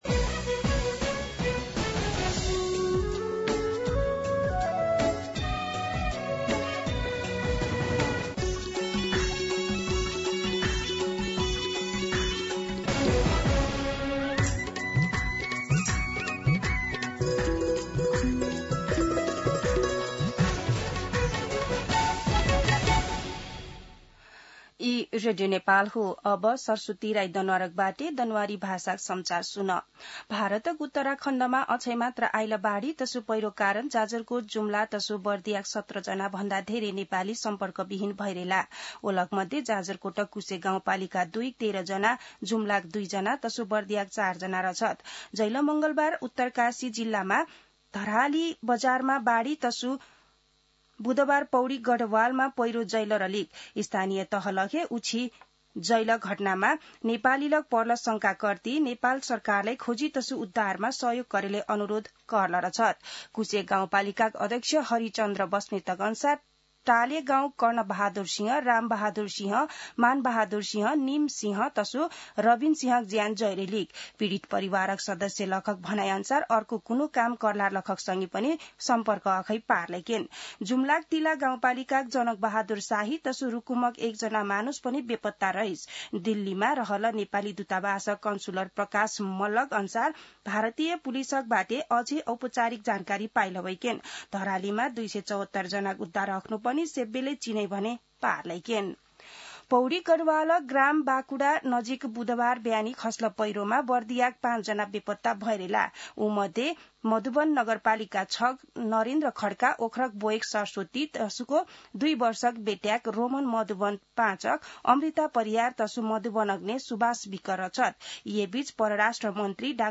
दनुवार भाषामा समाचार : २३ साउन , २०८२
Danuwar-News-.mp3